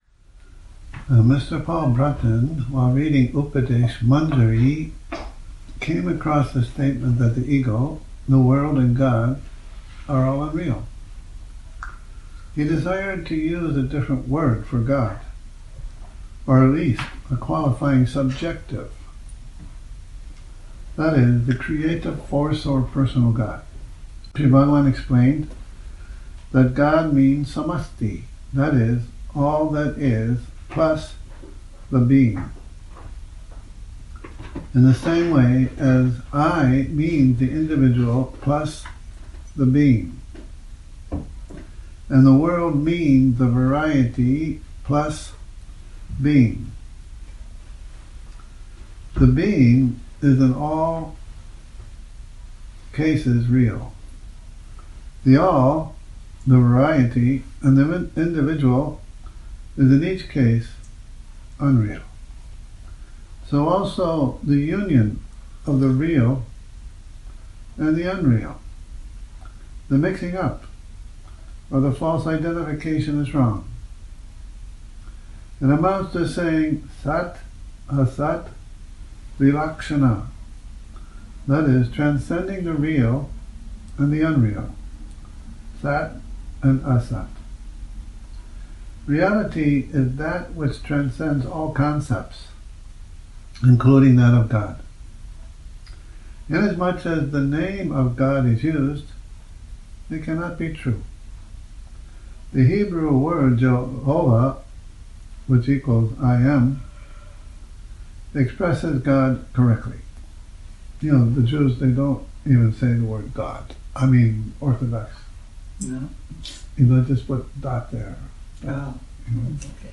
Morning Reading, 18 Nov 2019